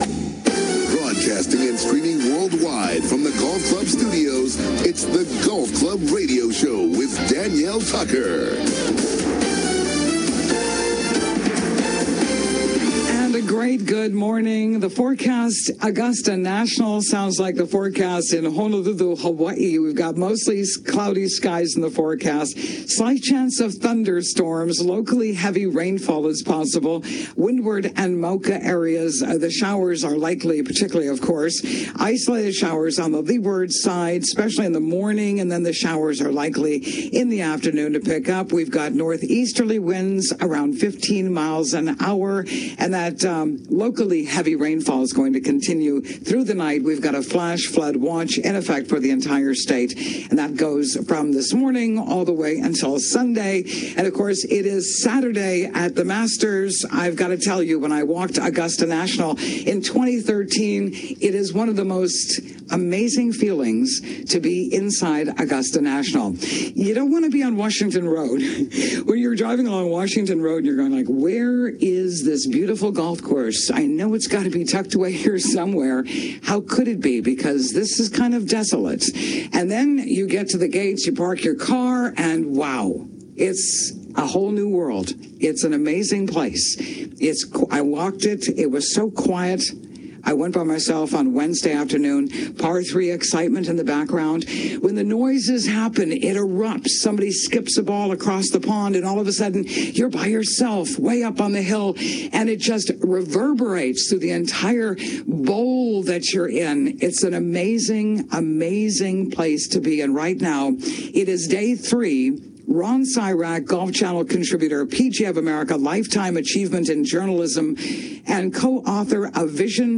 Live SATURDAY MORNINGS: 7:00 AM - 8:30 AM HST